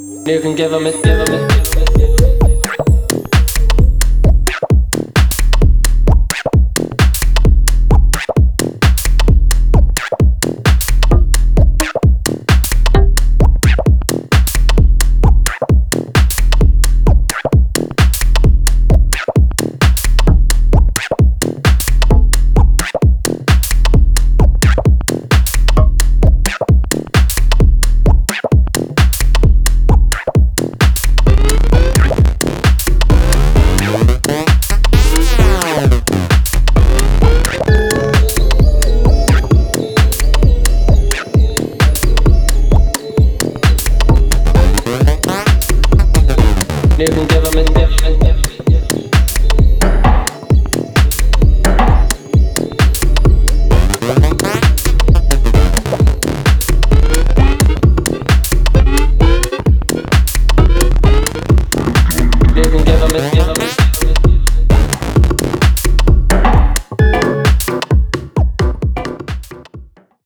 総じてグリッチーでアブストラクト、そしてエキセントリックな音色や、展開の作り込みの緻密さが印象的。